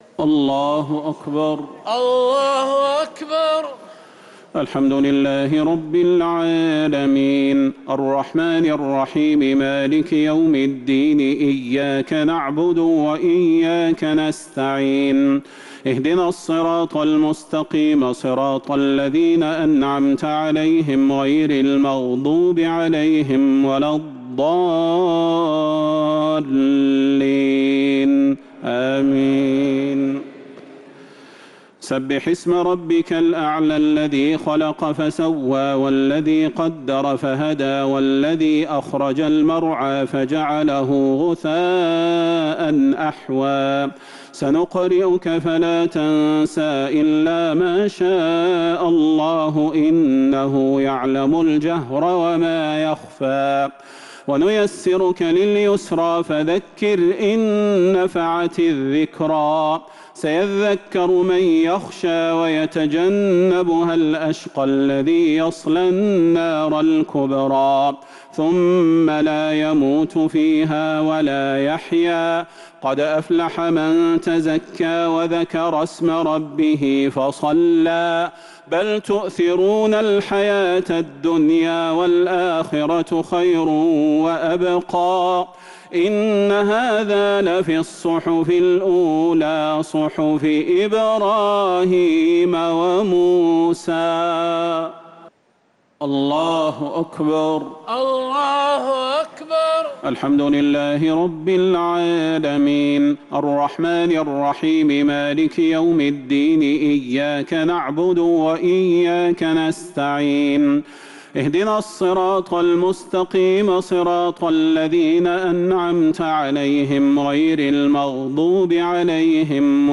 صلاة الشفع و الوتر ليلة 6 رمضان 1447هـ | Witr 6th night Ramadan 1447H > تراويح الحرم النبوي عام 1447 🕌 > التراويح - تلاوات الحرمين